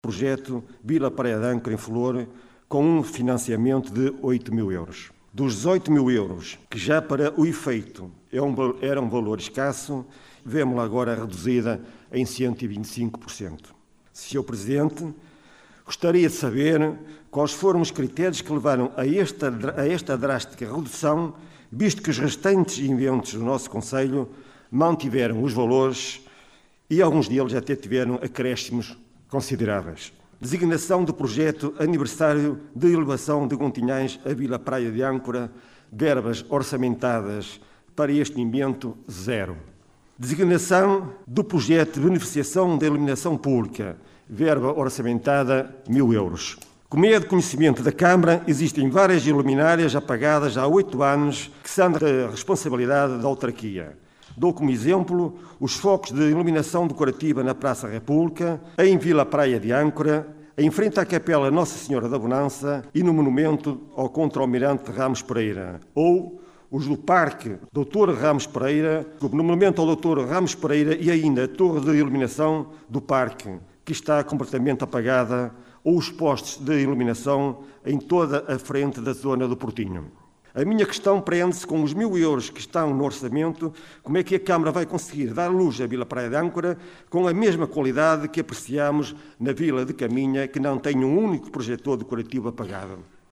Realizada no cineteatro dos bombeiros voluntários de Vila Praia de Âncora, decorreu em tom de crispação de início ao fim.